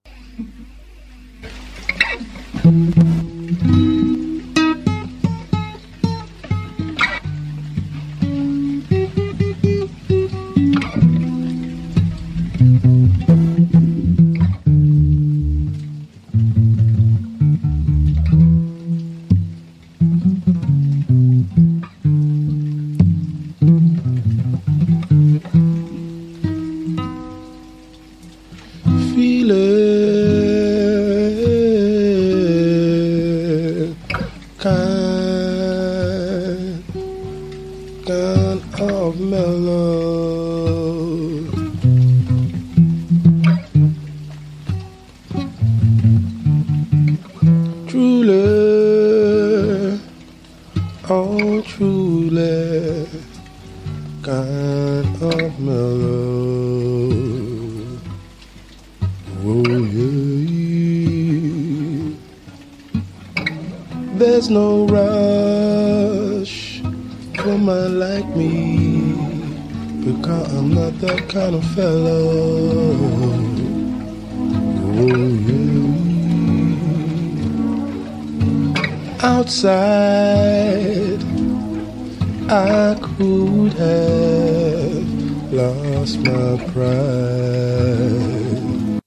SSW / FOLK (90-20’s)